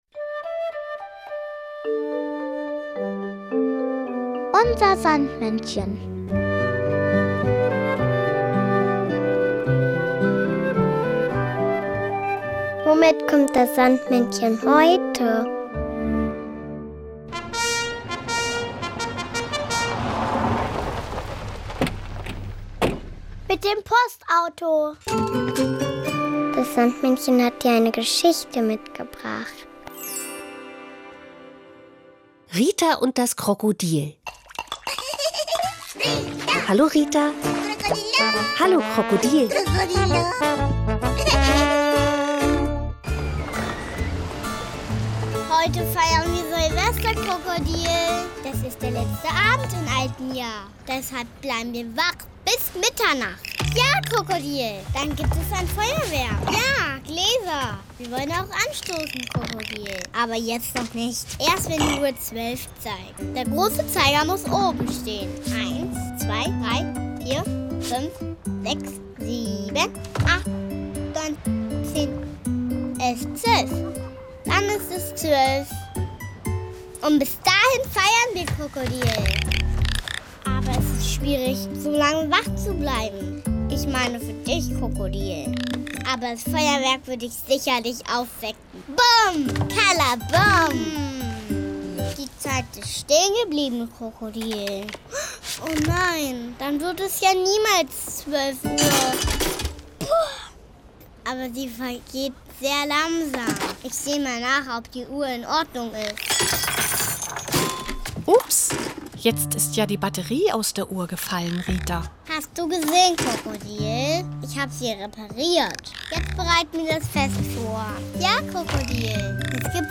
Kinderlieder